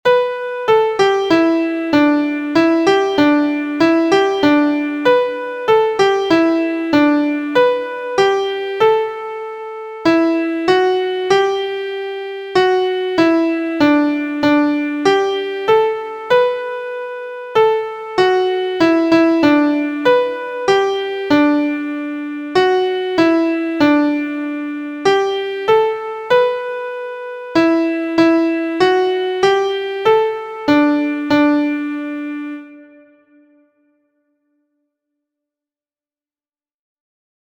• Origin: USA – Traditional
• Key: G Major
• Time: 2/4
• Form: ABA – refrain/verse/refrain